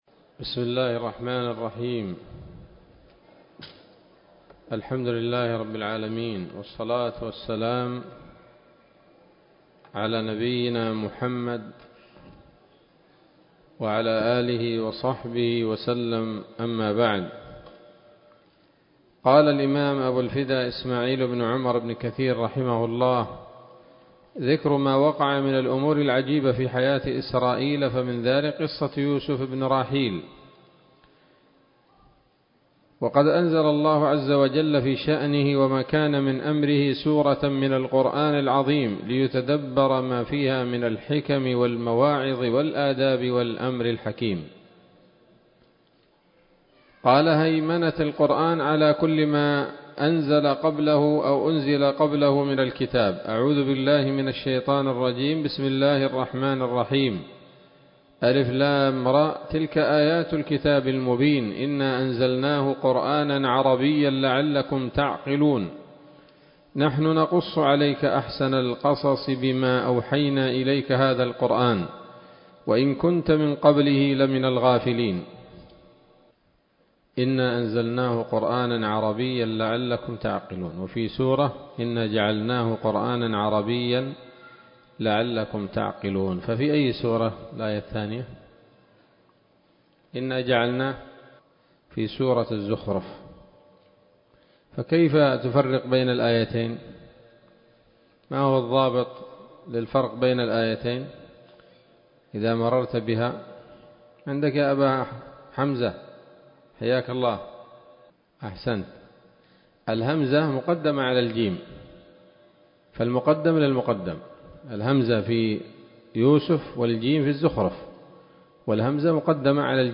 الدرس السادس والستون من قصص الأنبياء لابن كثير رحمه الله تعالى